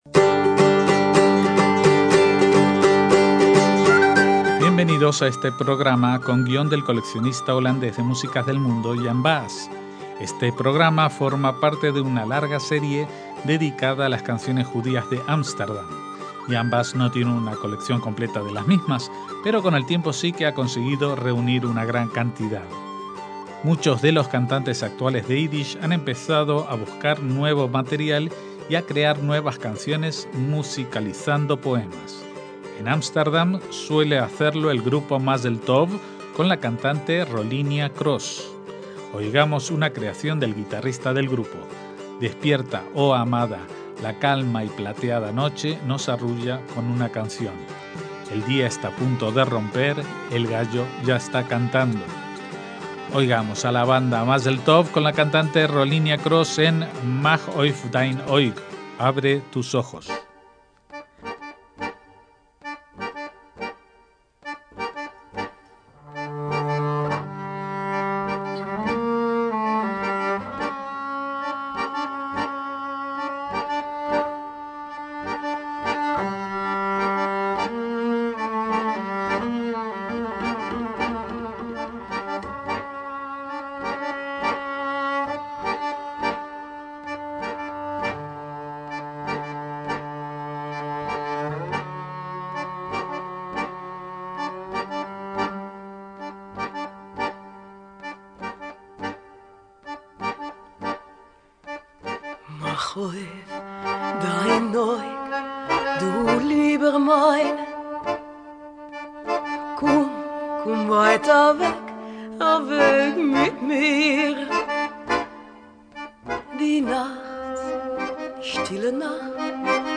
Esta es la séptima de las entregas que dedicaremos a músicos judíos de Ámsterdam a través de los tiempos, en esta ocasión en torno a nuevas canciones de temática judía.